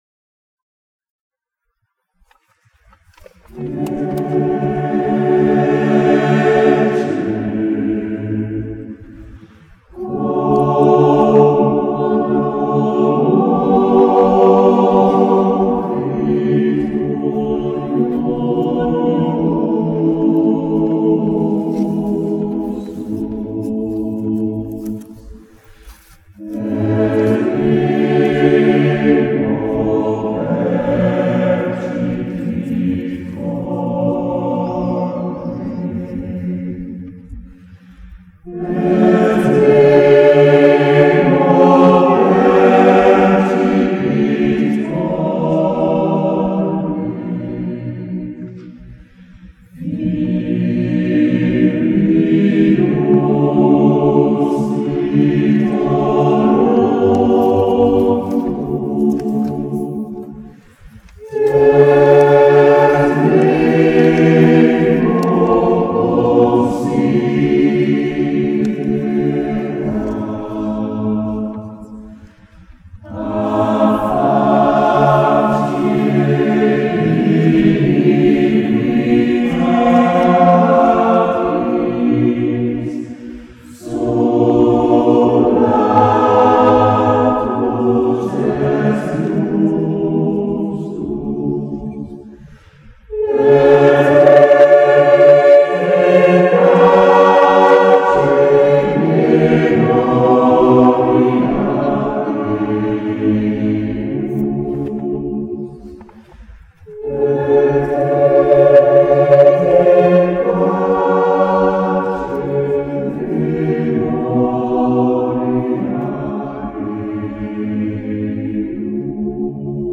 Sinds het begin van de zeventiende eeuw werd in Duitsland op Goede Vrijdag, na het slotkoor van elke gezongen passie, het korte motet Ecce, quomodo moritur van Jacob Handl gezongen.
met het Bachkoor